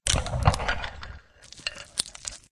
PixelPerfectionCE/assets/minecraft/sounds/mob/witherskeleton/say2.ogg at mc116